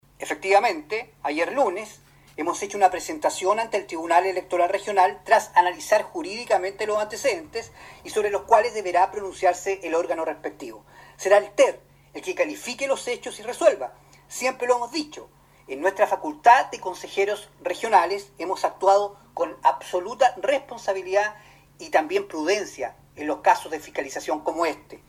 Al respecto confirmó la acción ante el tribunal electoral regional el consejero por la provincia de Osorno, Francisco Reyes.
25-CONSEJERO-FRANCISCO-REYES-.mp3